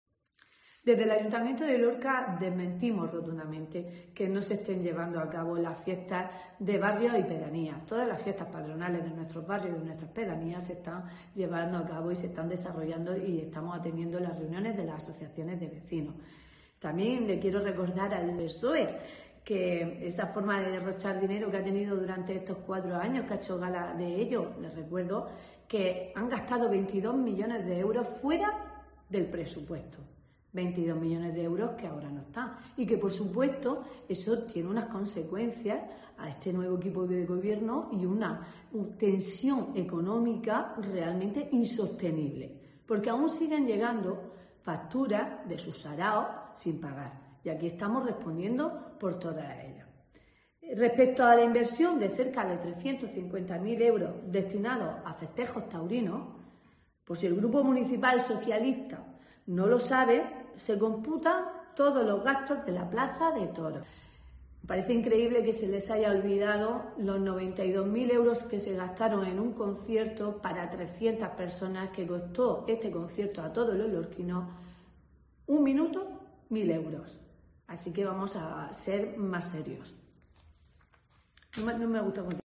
María de las Huertas García, concejala de Festejos